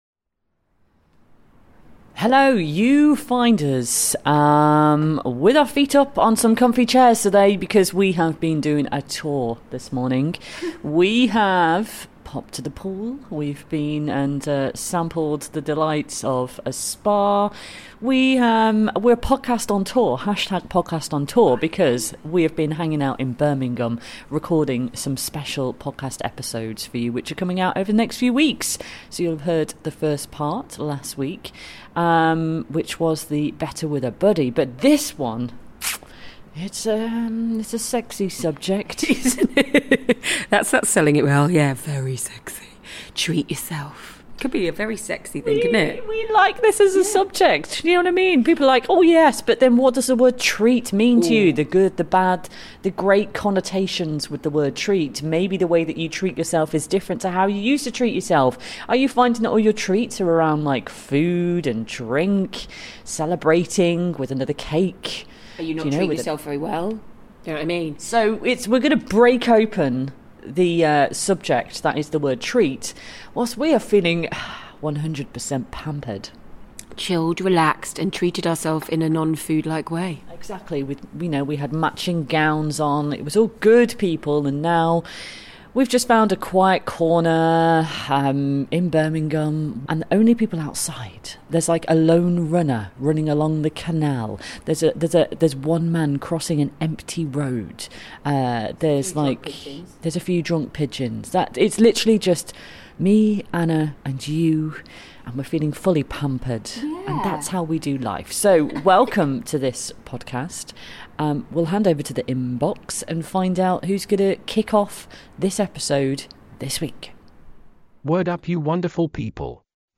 the girls have just finished putting their feet up, robes on, hanging out pool side of a posh hotel. They discuss what the word 'treat' can mean - good and bad, and discuss how we can be kind to ourselves without centring every celebration around food.